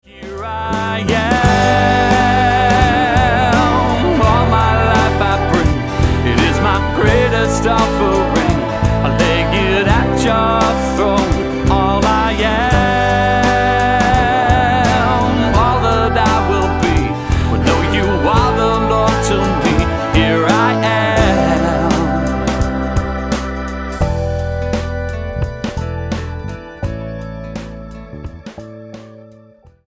STYLE: Roots/Acoustic
Guitar-based, but also including some appealing keyboards.